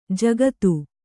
♪ jagatu